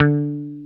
Index of /90_sSampleCDs/Roland LCDP02 Guitar and Bass/GTR_Dan Electro/GTR_Dan-O 6 Str